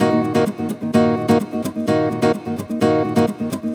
VEH2 Nylon Guitar Kit 128BPM